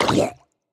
Minecraft Version Minecraft Version latest Latest Release | Latest Snapshot latest / assets / minecraft / sounds / mob / drowned / water / hurt2.ogg Compare With Compare With Latest Release | Latest Snapshot
hurt2.ogg